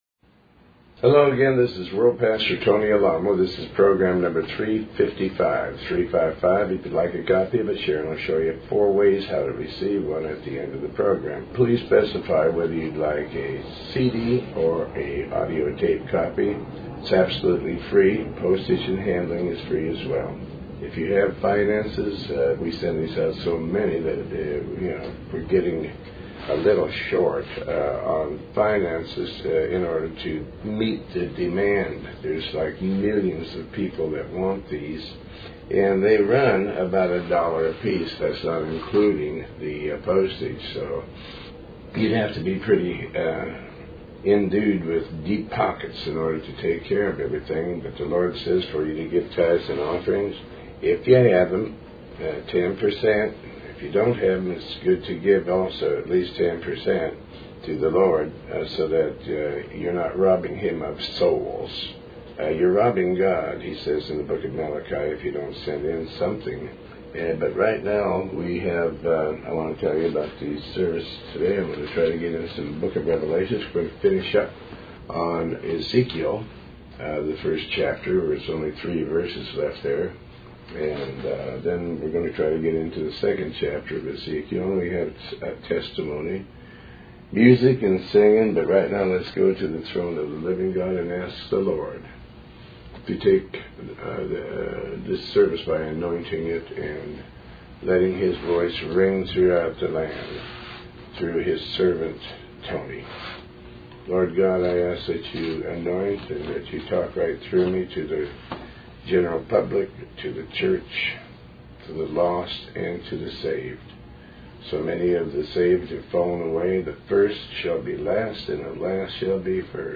Talk Show Episode, Audio Podcast, Tony Alamo and Program 355 on , show guests , about pastor tony alamo,Tony Alamo Christian Ministries,Faith, categorized as Health & Lifestyle,History,Love & Relationships,Philosophy,Psychology,Christianity,Inspirational,Motivational,Society and Culture